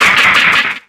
Cri de Ludicolo dans Pokémon X et Y.